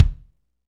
KIK FNK K01R.wav